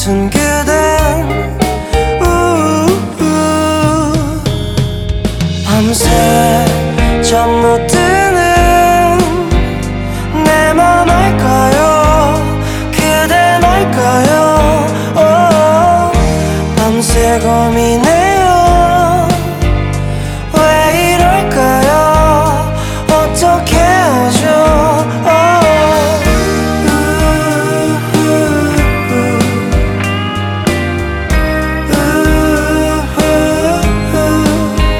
Жанр: Поп музыка / Соундтрэки